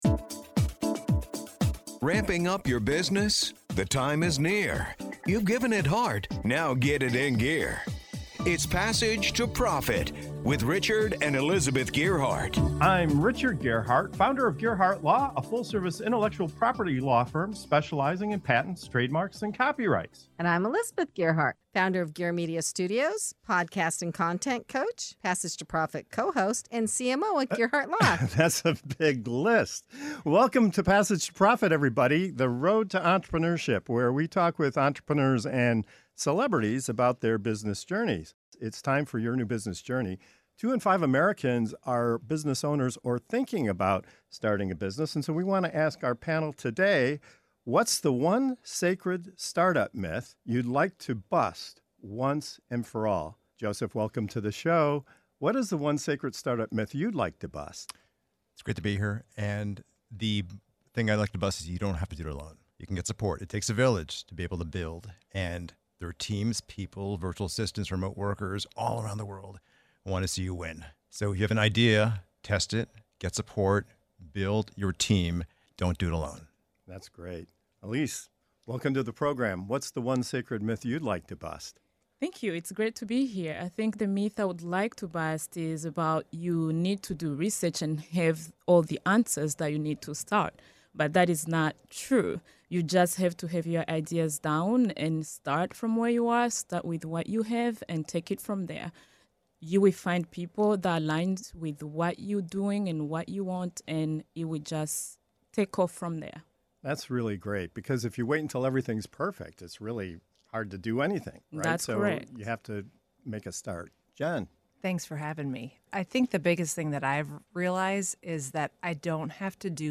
In this segment of "Your New Business Journey" on Passage to Profit Show, our panel of entrepreneurs bust the biggest startup myths holding founders back—from the idea that you have to go it alone, to the belief that success means never resting. Discover why starting small, doing less, and finding support can actually fuel your creativity, growth, and lasting success.